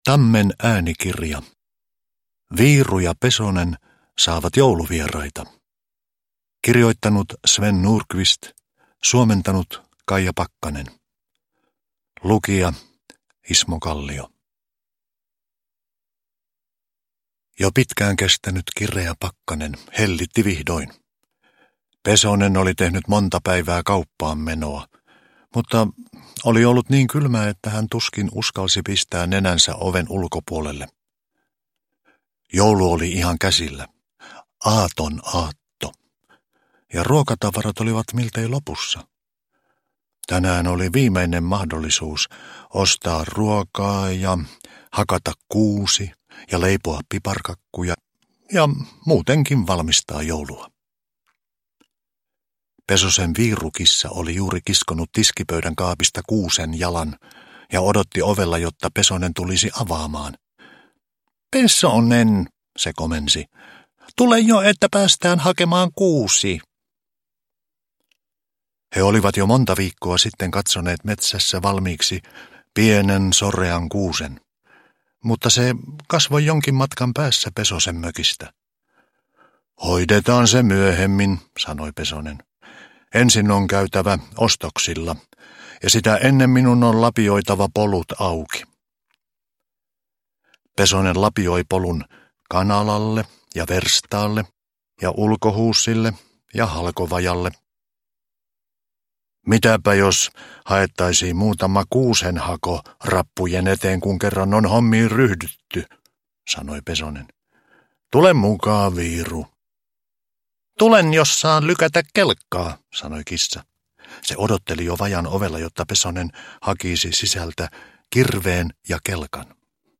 Viiru ja Pesonen saavat jouluvieraita – Ljudbok – Laddas ner
Uppläsare: Ismo Kallio